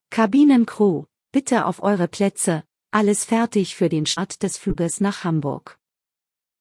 CrewSeatsTakeoff.ogg